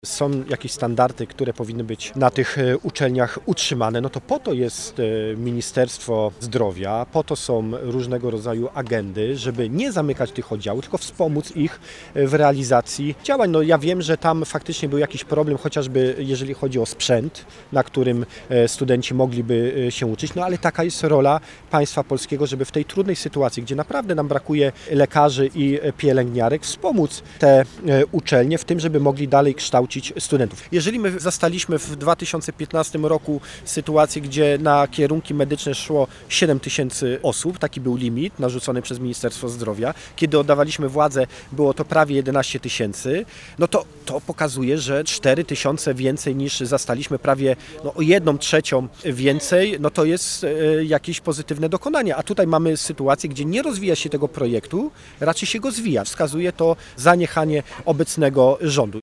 Wiceprezes PiS Elżbieta Witek, a także posłowie Paweł Hreniak, Jacek Świat oraz radni Sejmiku Województwa Dolnośląskiego spotkali się przy szpitalu uniwersyteckim we Wrocławiu, by zaapelować do rządu o podjęcie działań ws. ochrony i służby zdrowia.